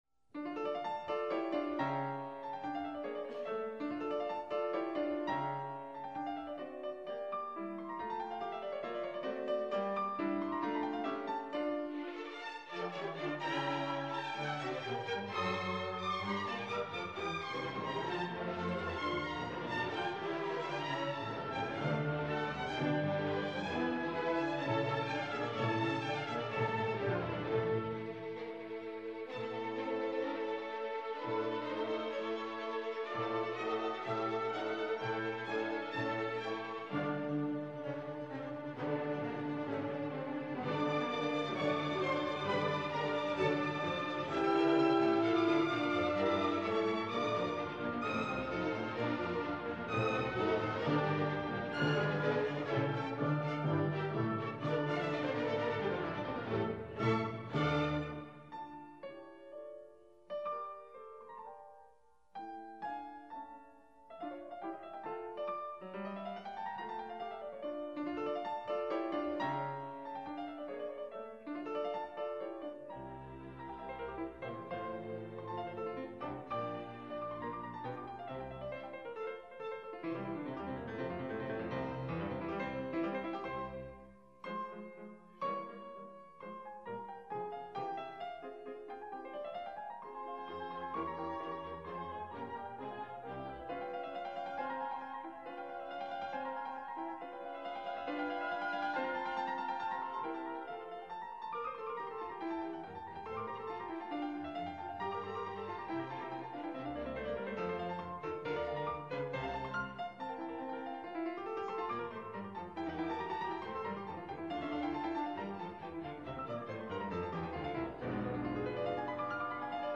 Piano
(Tracks 1-3, recorded: Jerusalem, 1/7/1977)